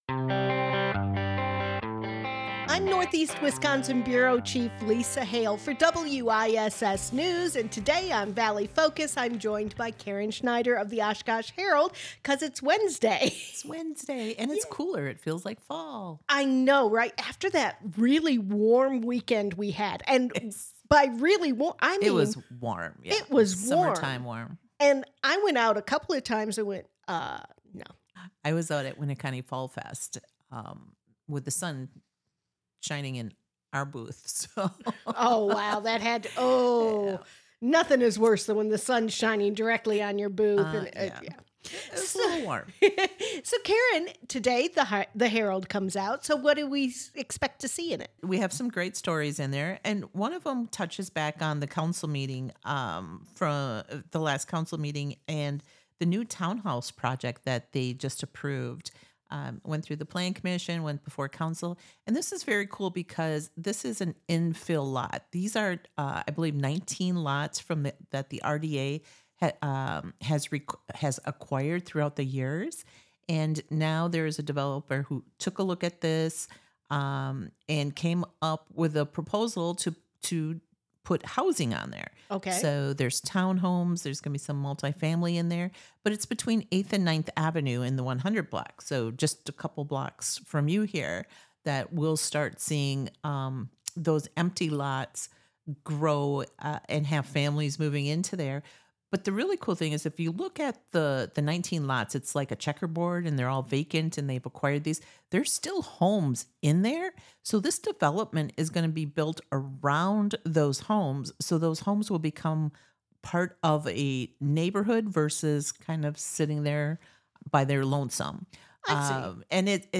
Conversations will range from entertainment to government to community involvement and more!